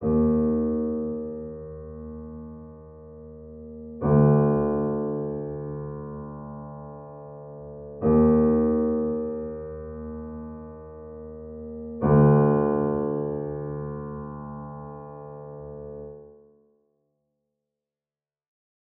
AV_Sneaky_Piano_60bpm_C#min
AV_Sneaky_Piano_60bpm_Cmin.wav